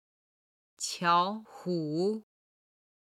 今日の振り返り！中国語発声
日本の企業ベネッセグループの「こどもちゃれんじ」で有名な「巧虎(qiǎo hǔ)しまじろう」が上海の地下鉄の車両狭しとデコレーション。